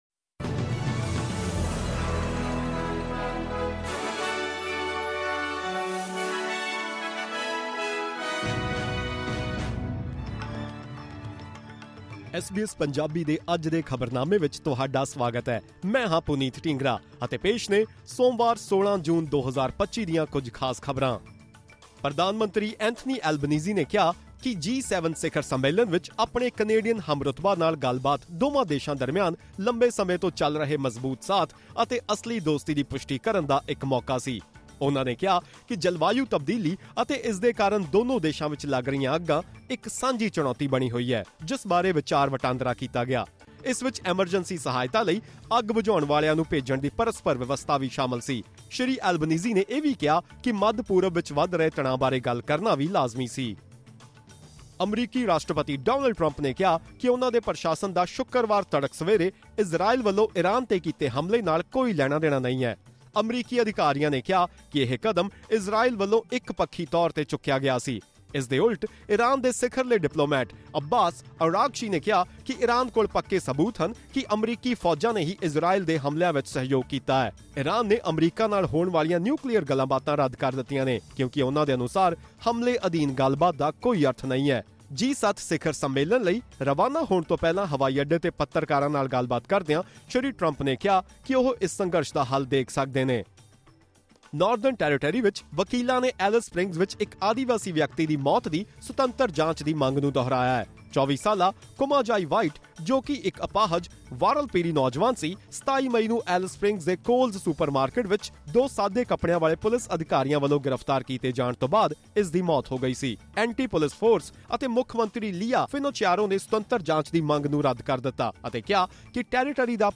The new regulations include a requirement to report allegations of physical or sexual abuse within 24 hours, instead of the current 7-day period. To learn more about these new measures and other news updates, listen to this news bulletin.